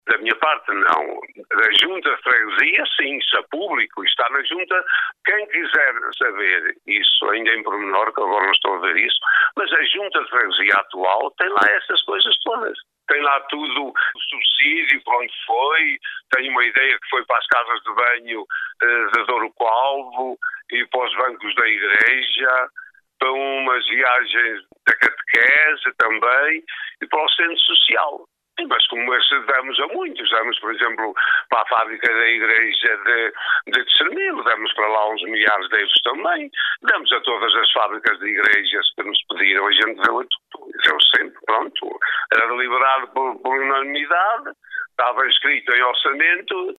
O ex-autarca Jorge Oliveira, quando questionado pela Alive Fm, pelo trabalho que o Centro Social e Paroquial de Romãs presta à população, Jorge Oliveira emocionou-se.